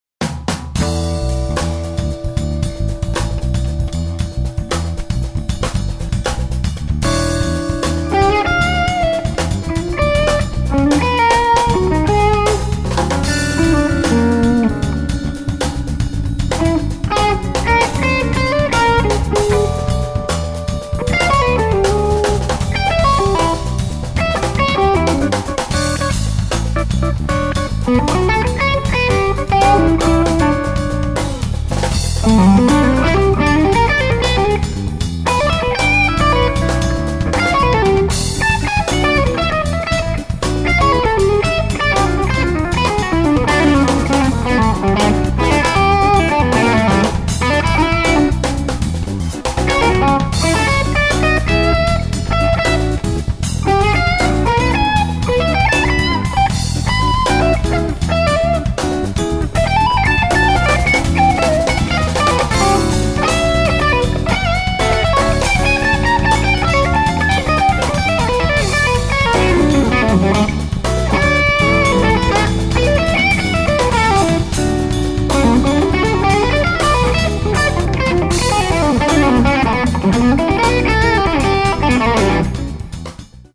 The gain is preamp IMO.
Here is a Fordish one from me...
My latest rig is SM57 into Groove Tubes Brick preamp into EMU1820M breakout box and soundcard.